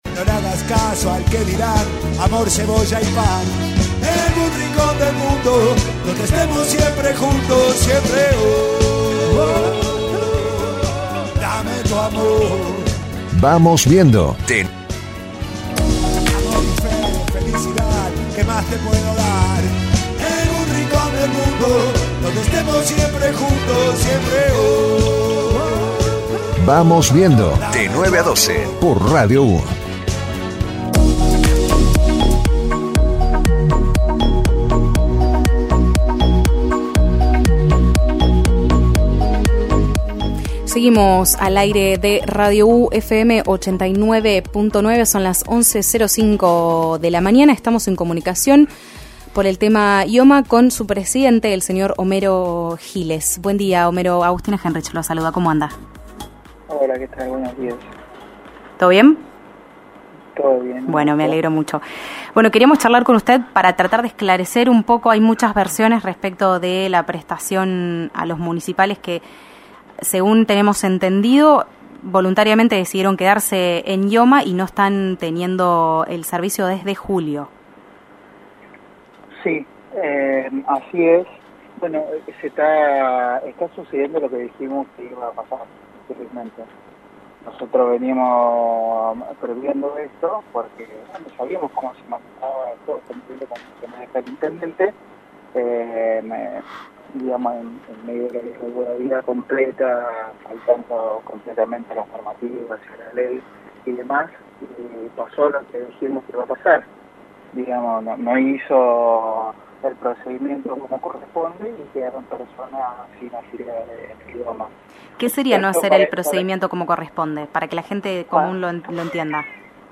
Así lo expresó Homero Giles en Radio U FM 89.9 de San Nicolás en relación a la medida del intendente de esa ciudad que dejó sin afiliación a los trabajadores municipales de ese distrito. En la entrevista remarcó que deben cumplirse los procedimientos establecidos por las normas para que las y los trabajadores municipales puedan afiliarse en forma voluntaria a IOMA, en cumplimiento de la medida cautelar dispuesta recientemente.